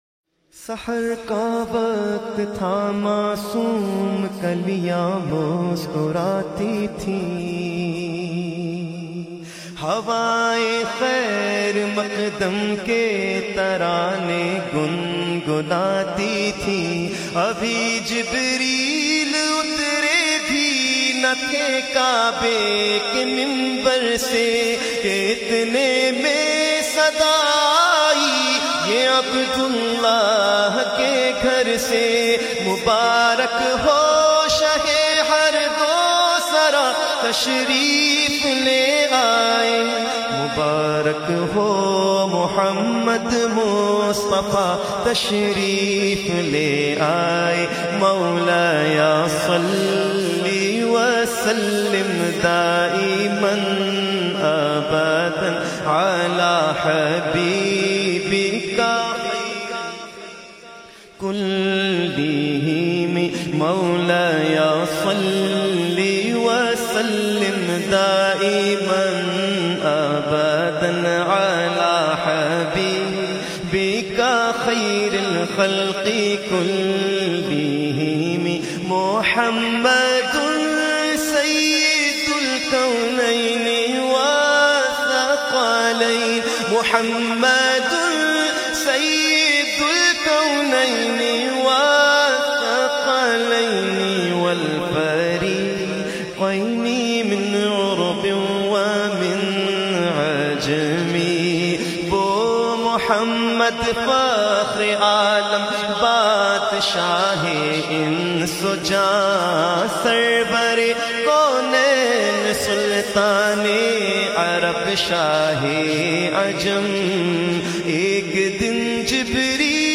Beautifull Naat